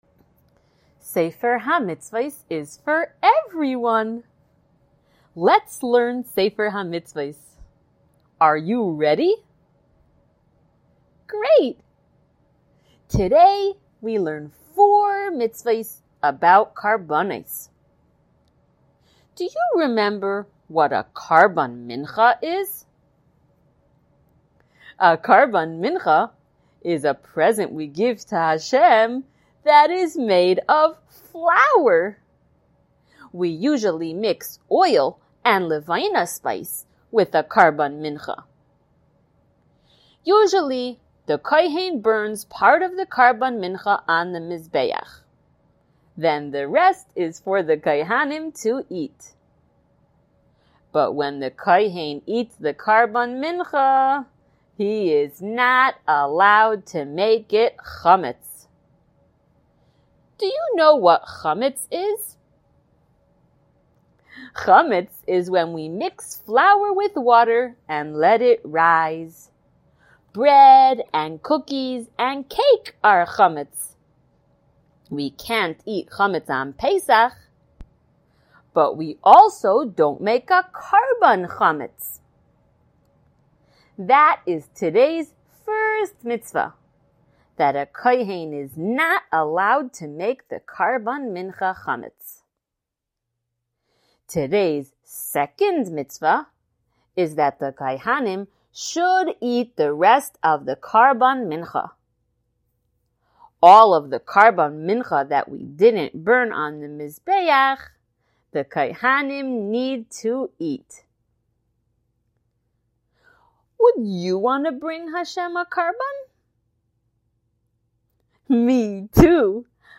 Color Shiur #163!